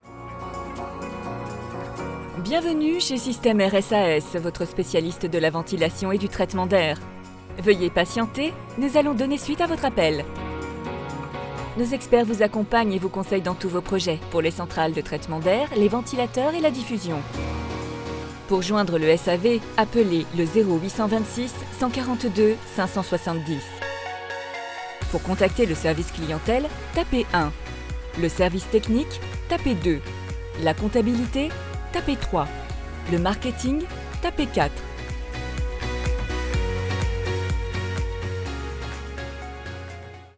IVR, Dynamique, corporate, sérieuse